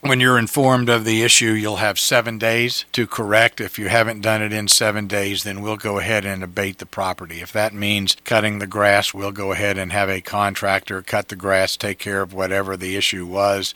Mayor Ray Morriss said the revision to the timeline allows the city to act faster…